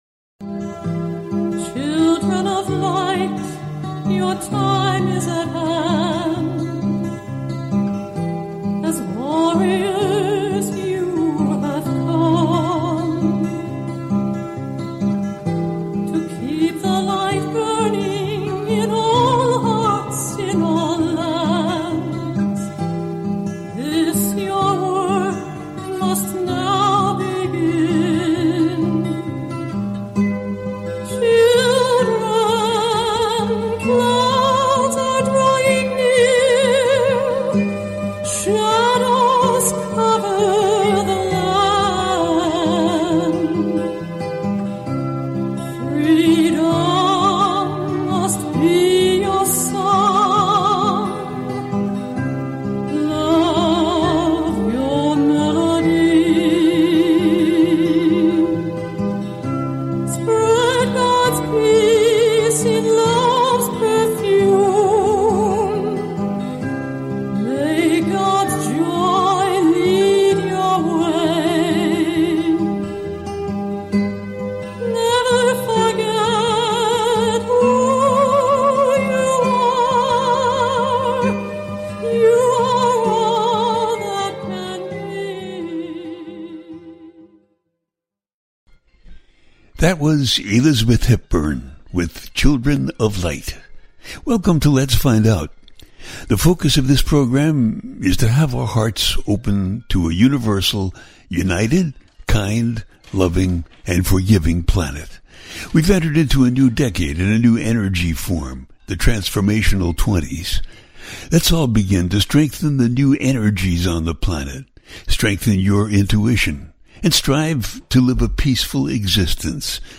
The listener can call in to ask a question on the air.
Each show ends with a guided meditation.